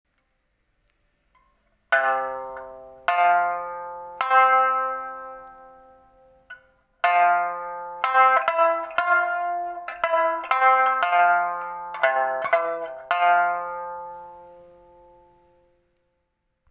三線の型：南風原
皮の張り：蛇皮強化張り
黒檀は縞模様が美しく、紫檀よりは高音に伸びがあり、中低音域に広がりがあるのが特徴。
この棹に強化張りの組み合わせでは高音が耳障りな感があると思い、弦はポリエステル弦を張った。
結果は思惑通り、中低音域はしっかりとした広がりがあり、まろやかで穏やかな音色に仕上がった。